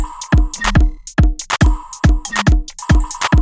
Perfect for beat, beats, drumkit.
beat beats drumkit fast Gabber hardcore House Jungle sound effect free sound royalty free Music